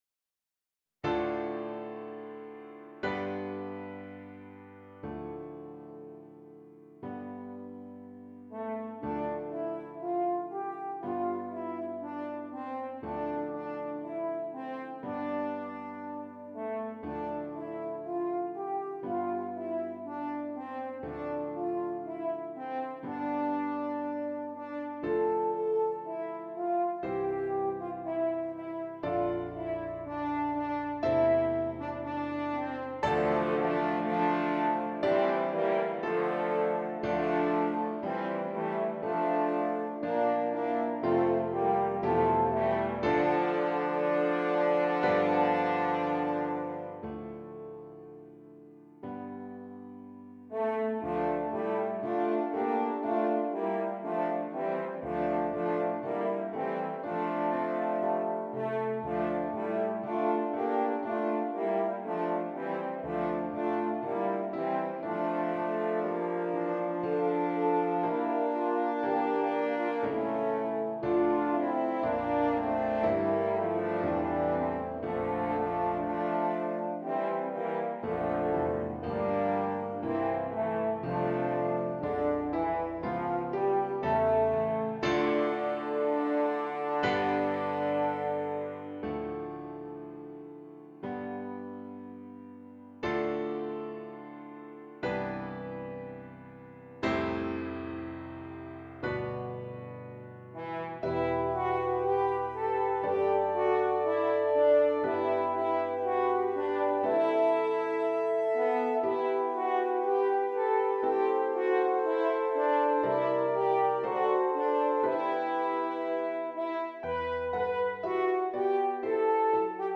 Christmas
3 F Horns and Keyboard
Traditional